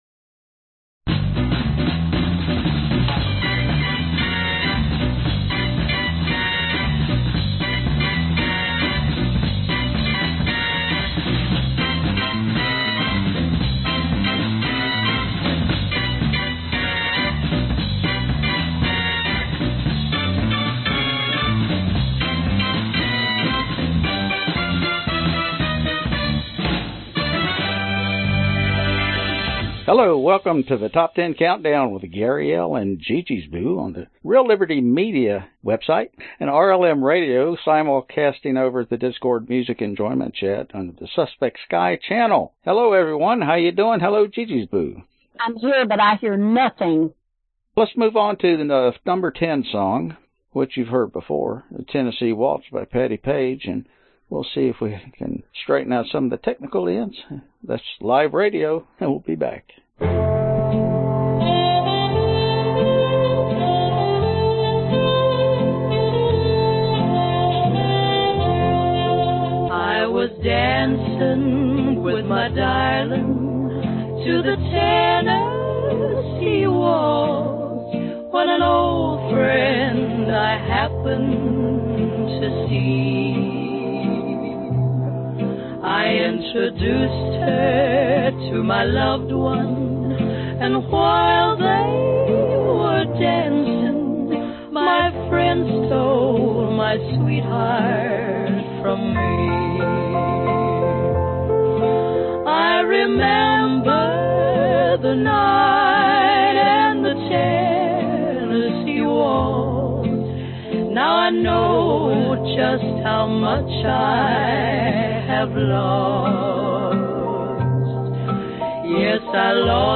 Genre Oldies